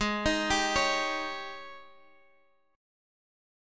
G#M7sus4 Chord